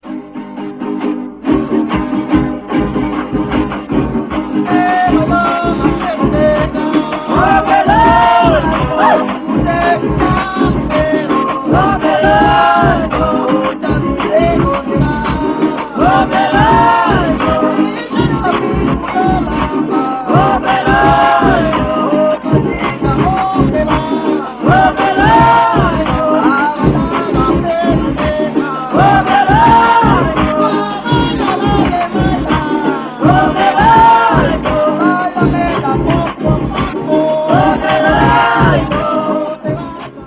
TAMUNANGUE
Es un Son de carácter responsorial; esta pieza es alegre pero sin sobrepasar en este aspecto al Poco a Poco.
El solista comienza con el estribillo y luego con la primera copla y el coro intercala su expresión "Ay tó".
La melodía se realiza en forma descendente para el estribillo y los finales de frase.
El canto de marcha se realiza en pies binarios y ternarios ajustándoles el compás de 6/8 y en otros el de 2/4. Los instrumentos de cuerda siempre usan 6/8. Como expresión complementaria se exclama: "Au, au, au", de manera tal que se superpone al coro.